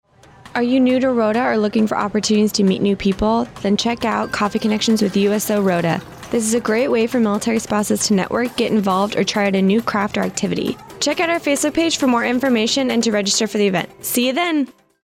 AFN Rota Radio Spot, Coffee Connections with the USO